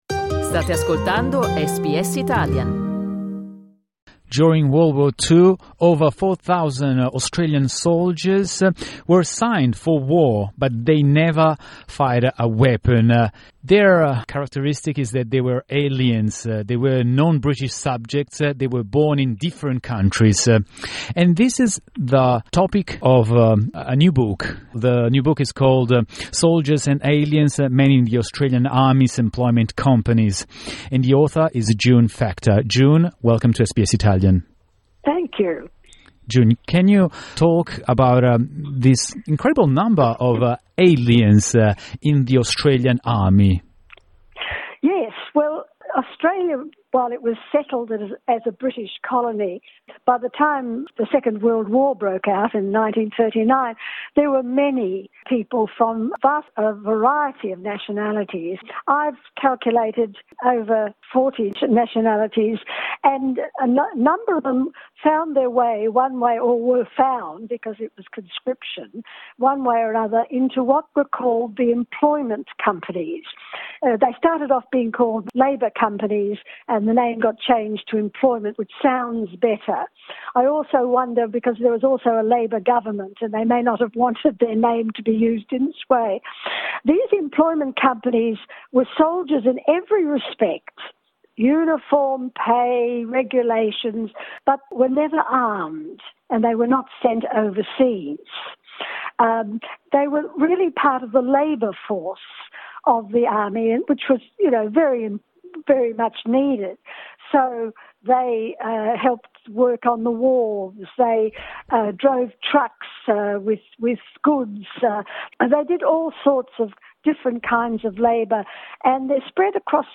Listen to her interview with SBS Italian.